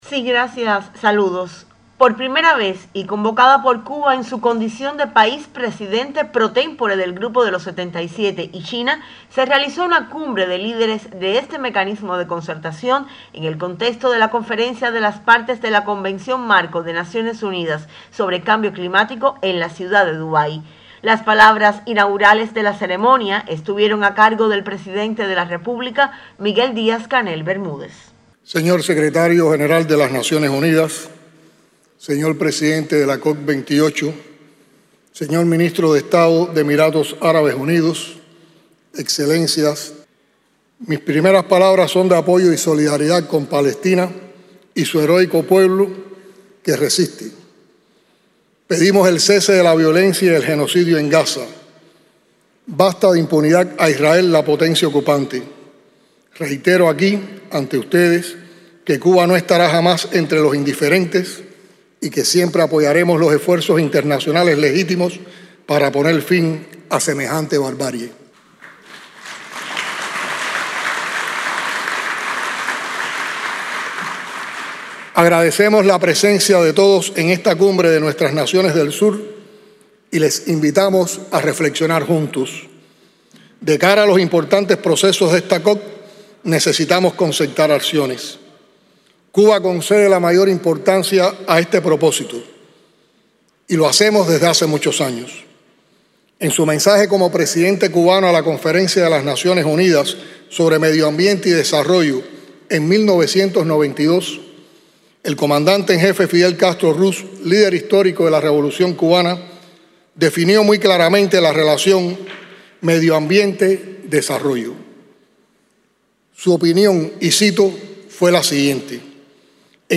En un encuentro histórico para los destinos de los países del Sur, el Presidente de la República de Cuba, Miguel Díaz-Canel Bermúdez, tuvo a su cargo, este sábado en la mañana (hora local), el discurso de apertura de la Cumbre de Líderes del Grupo de los 77 y China, la cual sesionó en el contexto de la COP28.
discurso_cumbre_g77_y_china.mp3